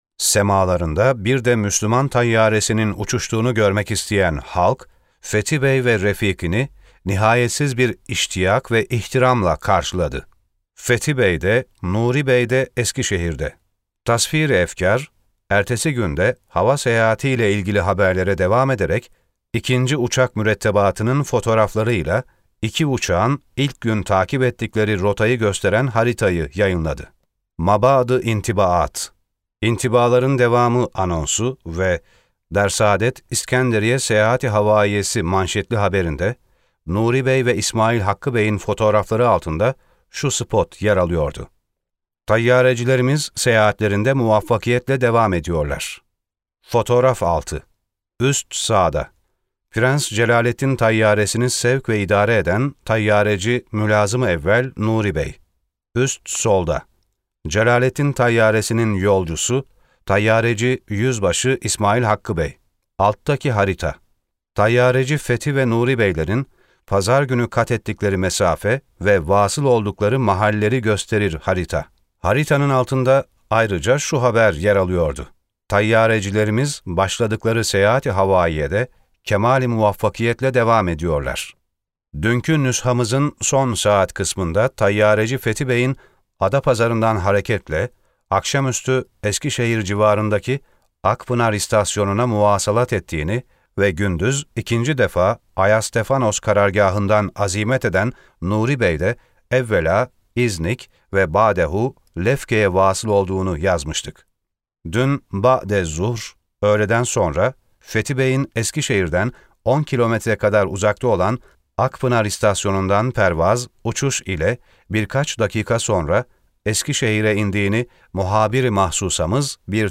SESLİ KİTAP – 1914 – İstanbul – Kudüs – İskenderiye Hava Seyahati